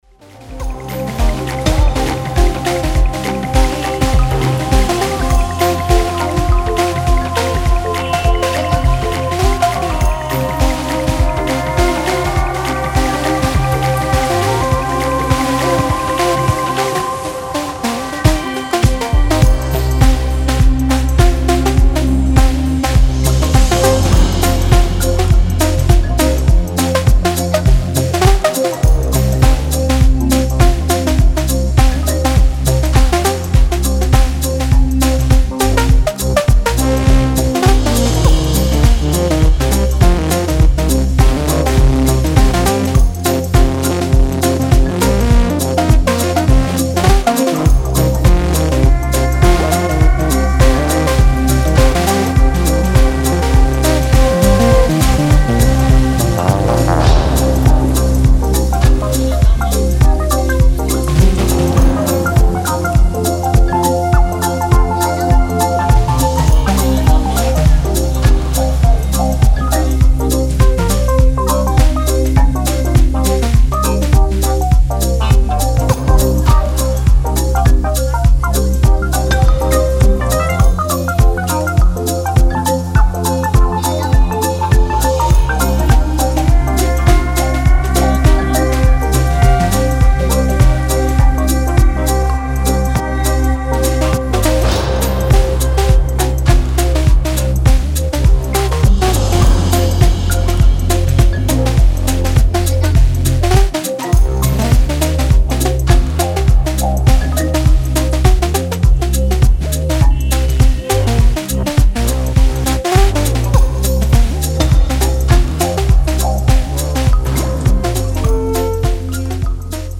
Style: House / Deep / Chill Out